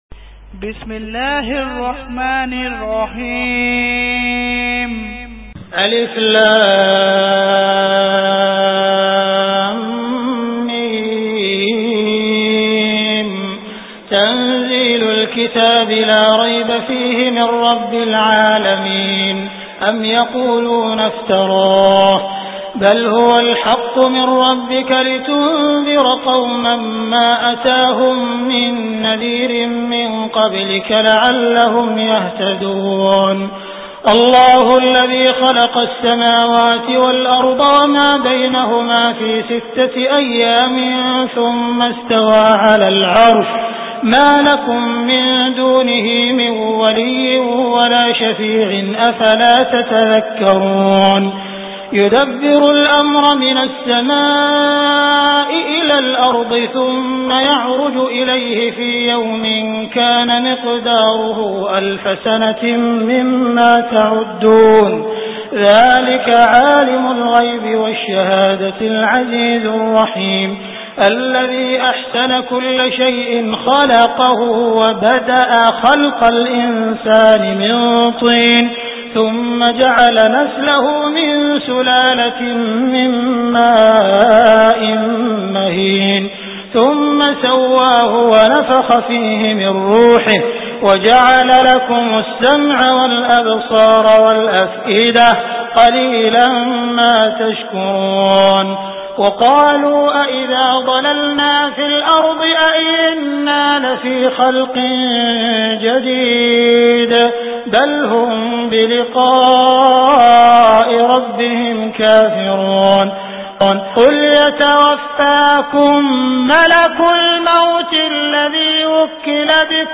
Surah As Sajdah Beautiful Recitation MP3 Download By Abdul Rahman Al Sudais in best audio quality.
surah as sajdah surah as sajdah mp3 surah as sajdah mp3 download surah as sajdah audio free download surah as sajdah tilawat mp3 surah as sajdah tilawat audio downl surah as sajdah free download surah sajdah sudais mp3 download surah sajdah sudais mp3 f